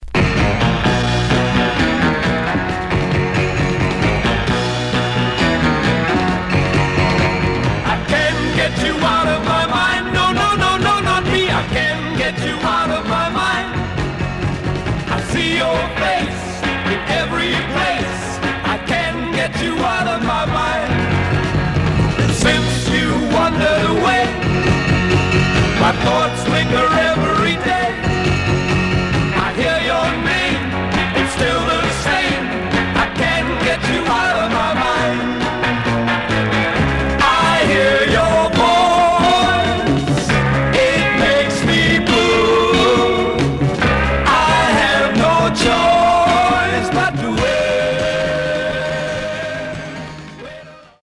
Stereo/mono Mono
Garage, 60's Punk Condition